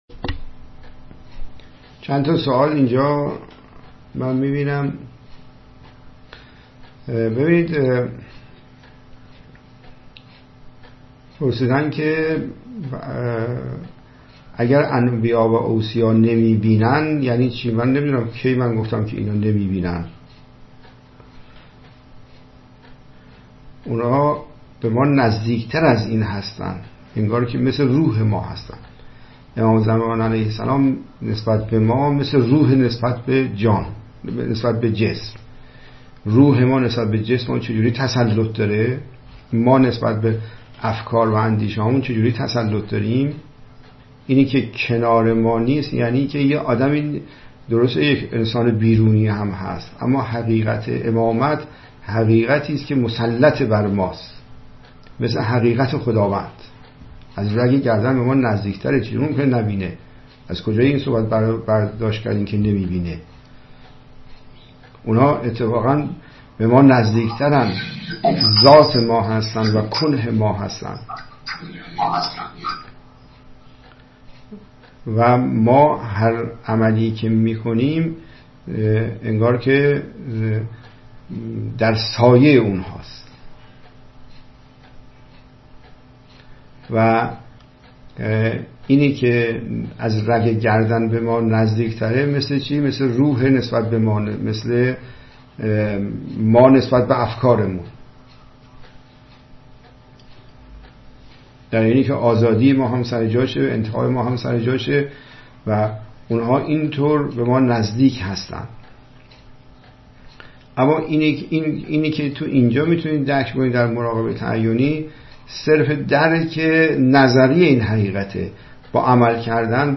متن : گفت‌وگو